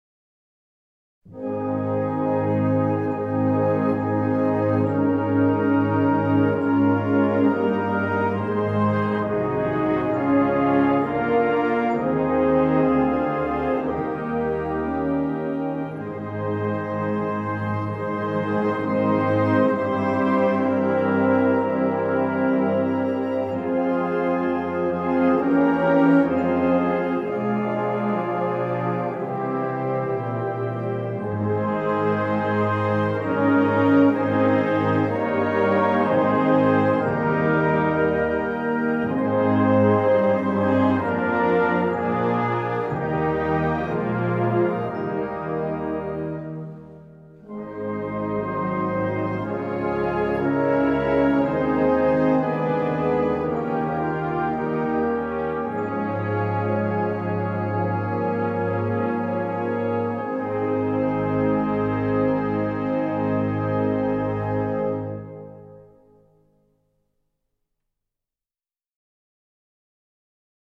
Gattung: Choralsammlung
Besetzung: Blasorchester